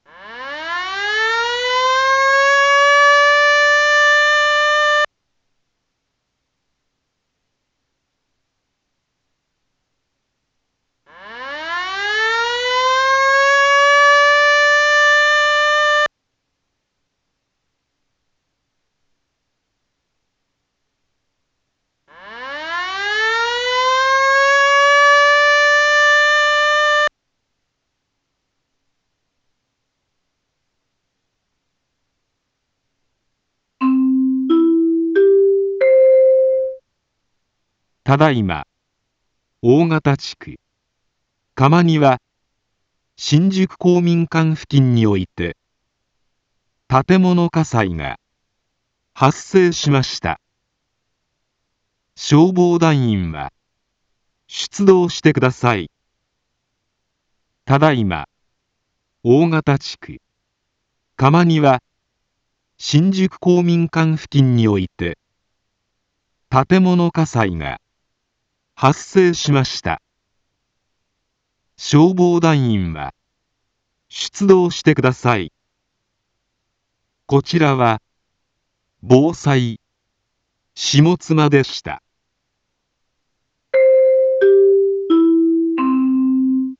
一般放送情報
Back Home 一般放送情報 音声放送 再生 一般放送情報 登録日時：2025-08-19 16:04:46 タイトル：火災報 インフォメーション：ただいま、大形地区、鎌庭、新宿公民館付近において、 建物火災が、発生しました。